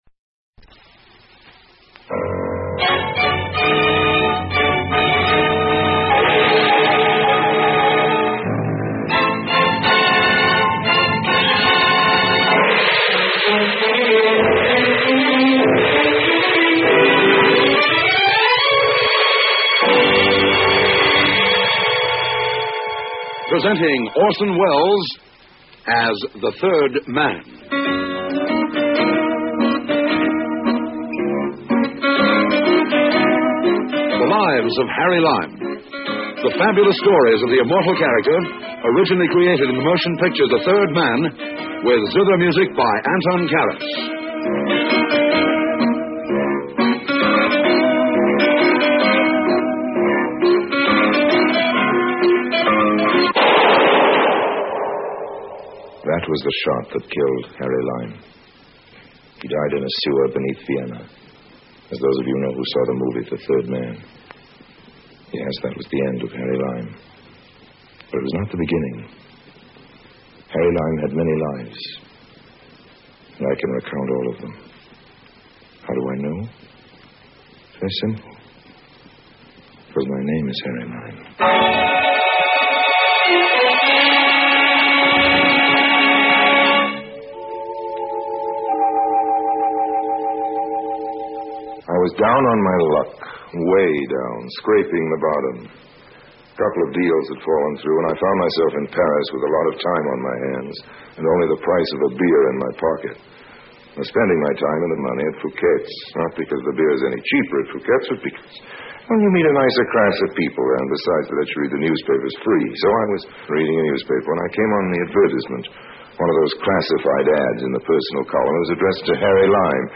The Adventures of Harry Lime is an old-time radio programme produced in the United Kingdom during the 1951 to 1952 season. Orson Welles reprises his role of Harry Lime from the celebrated 1949 film The Third Man. The radio series is a prequel to the film, and depicts the many misadventures of con-artist Lime in a somewhat lighter tone than that of the film.